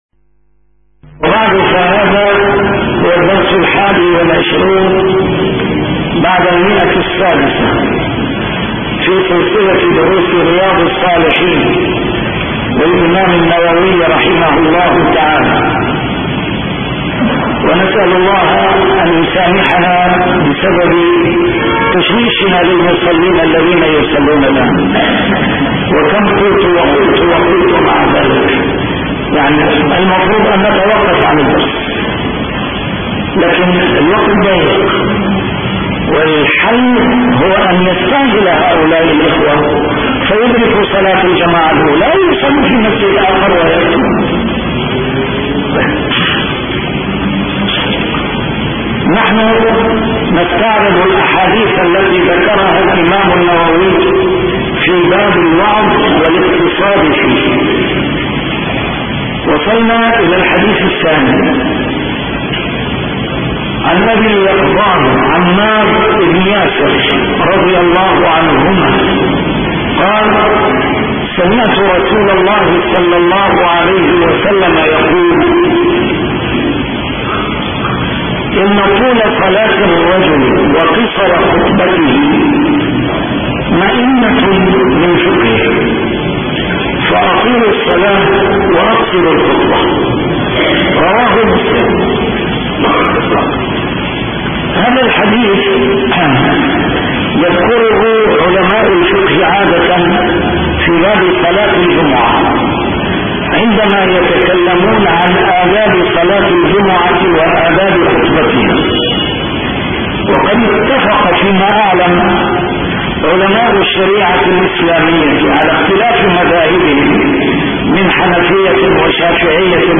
A MARTYR SCHOLAR: IMAM MUHAMMAD SAEED RAMADAN AL-BOUTI - الدروس العلمية - شرح كتاب رياض الصالحين - 621- شرح رياض الصالحين: الوعظ والاقتصاد فيه